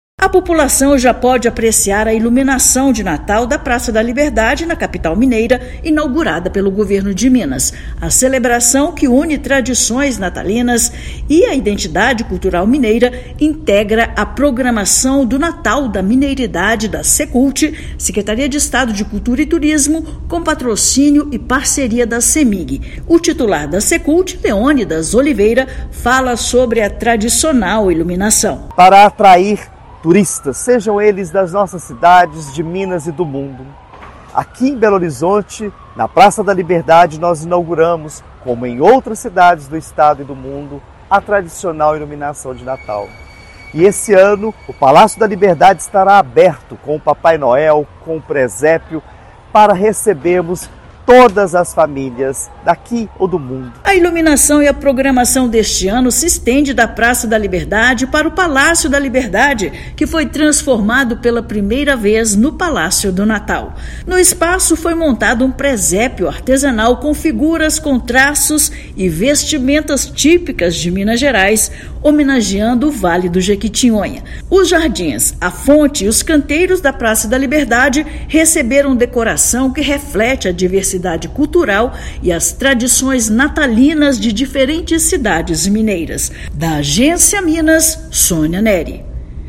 Projeto integra o Natal da Mineiridade e proporciona uma experiência sensorial diferente no Circuito Liberdade, incluindo a inédita transformação do Palácio da Liberdade no 'Palácio do Natal'. Ouça matéria de rádio.